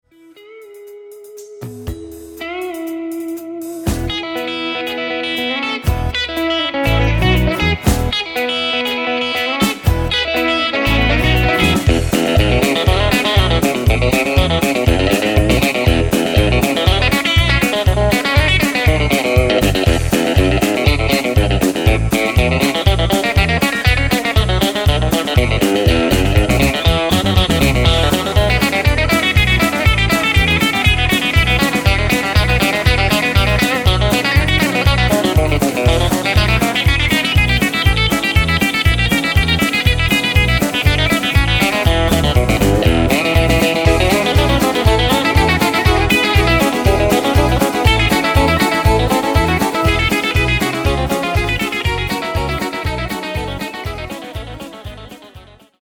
Vancouver Guitarist